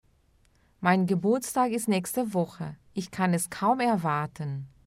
Veja abaixo vários exemplos com áudio para treinar a pronúncia e aumentar ainda mais o seu vocabulário em alemão.